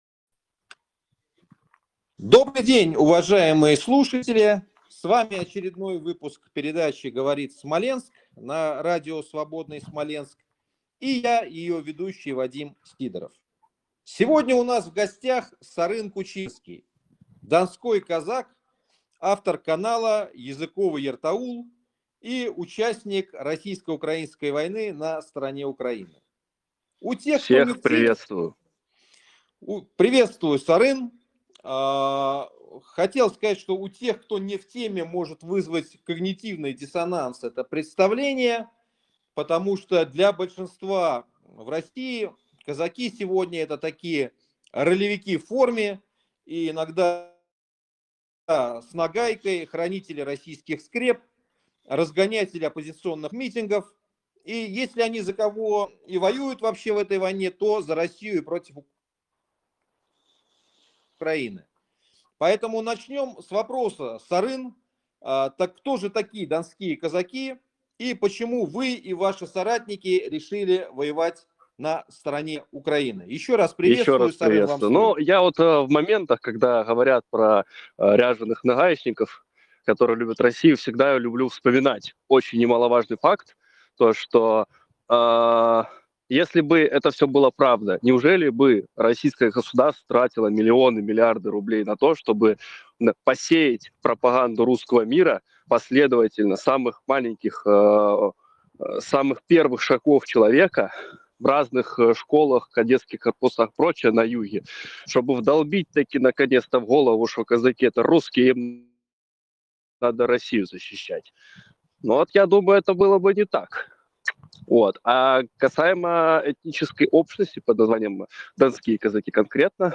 Гостем очередной передачи «Говорит Смоленск» на радио «Свободны Смаленск»